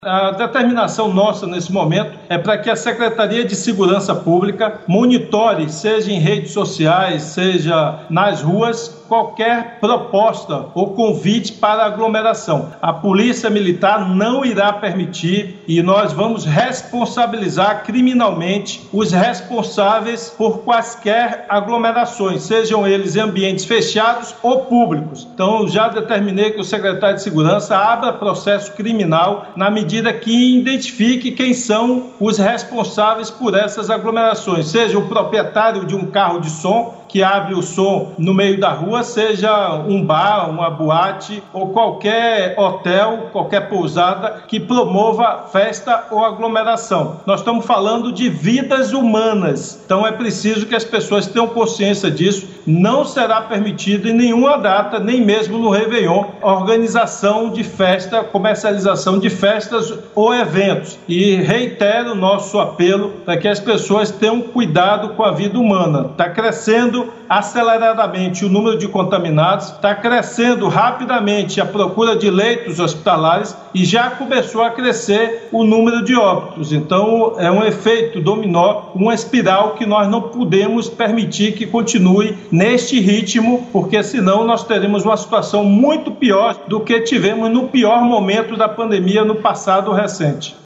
Em entrevista à TV Bahia, nesta terça-feira (8), o governador Rui Costa voltou a falar sobre a proibição das festas de final de ano na Bahia. Além de monitorar a mídia e as redes sociais para coibir a realização dos eventos, Rui afirmou que determinou que a Secretaria da Segurança Pública (SSP) abra processo criminal contra quem promover qualquer tipo de aglomeração.